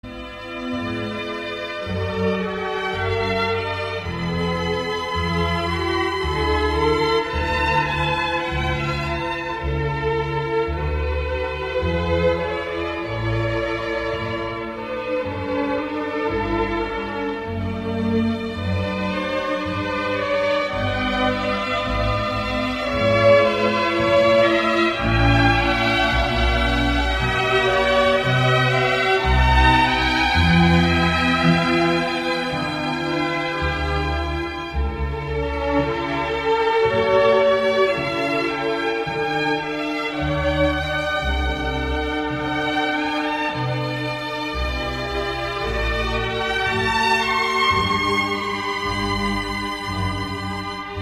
Kategorien Klassische